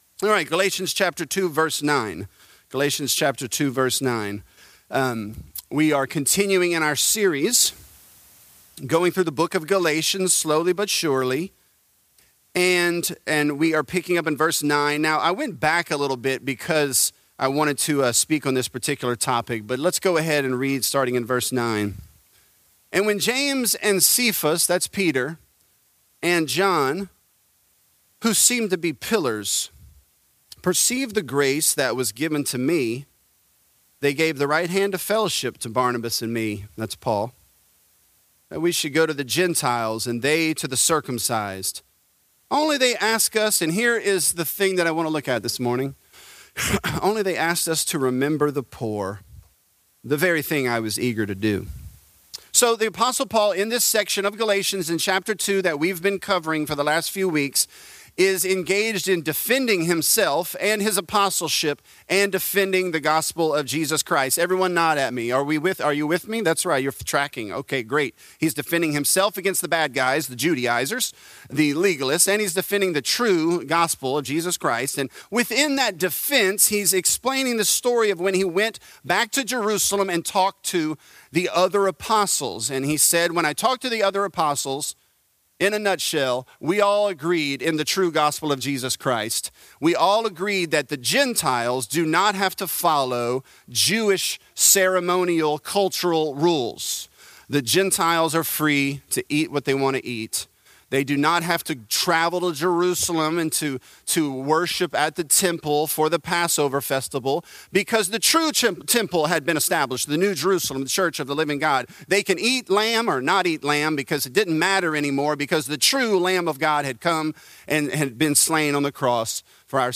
Galatians: Remember The Poor | Lafayette - Sermon (Galatians 2)